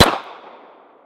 pistol-shot2.ogg